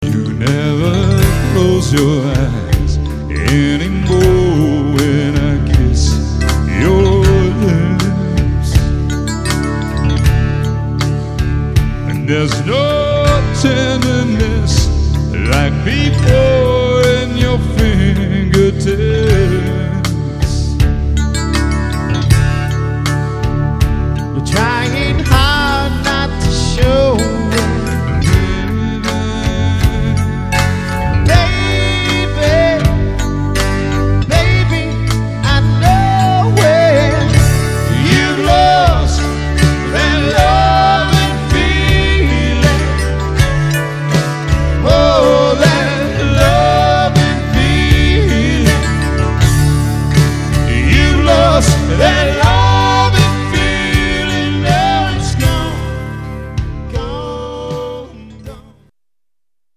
Lead Vocals, Keyboards, Percussion Programming
Electric Guitar, Lead & Background Vocals